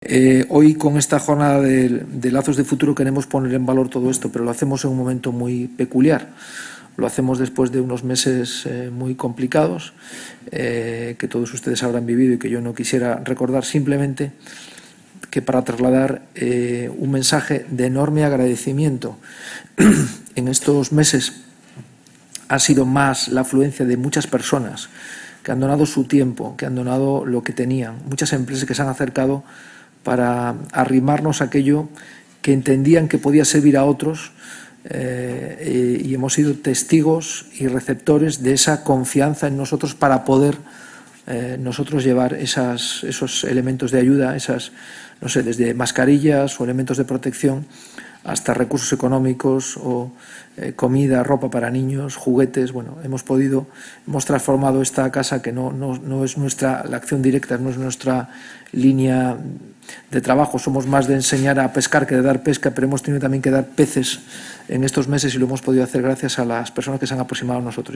La sede madrileña de Fundación ONCE reunió a estas 25 empresas y entidades en el  acto ‘Lazos de futuro’, celebrado el 18 de noviembre de manera presencial y telemática, con el objetivo de expresar públicamente el agradecimiento de las fundaciones del Grupo Social ONCE a los particulares, empresas e instituciones que colaboran con ellas a través de programas, proyectos e iniciativas que ayudan a avanzar en la igualdad de los derechos de las personas con discapacidad y sus familias, y por su compromiso con la mejora de la calidad de vida de las personas con discapacidad.